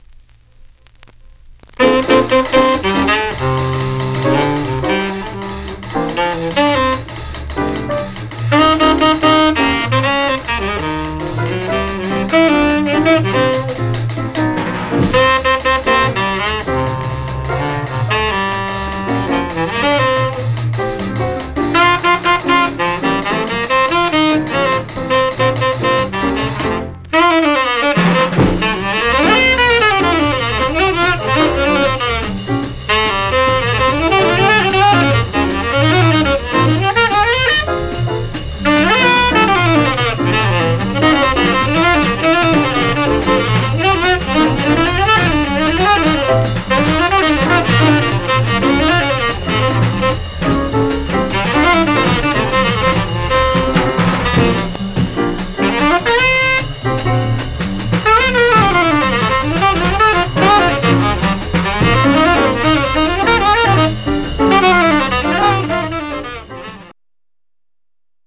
Surface noise/wear Stereo/mono Mono
Jazz (Also Contains Latin Jazz)